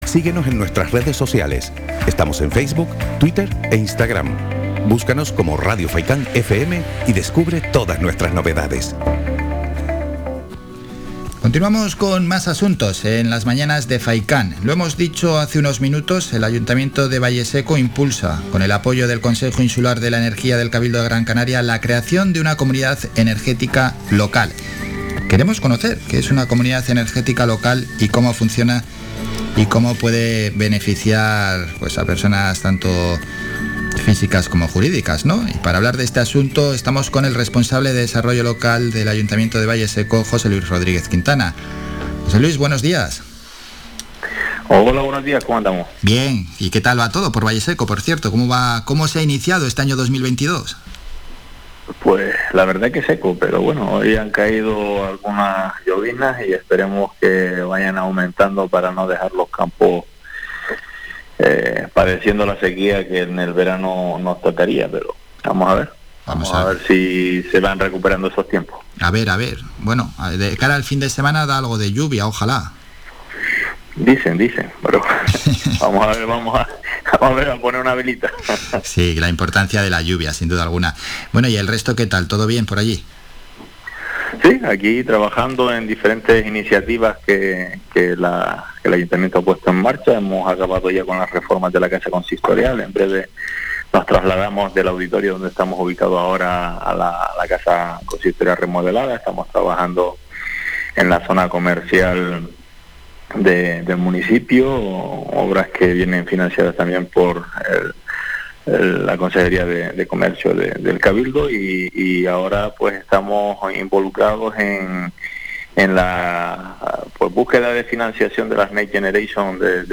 En el programa Las Mañanas de Faycán queríamos conocer en qué consiste una comunidad energética local. Para ello, hablamos con el concejal de Desarrollo Local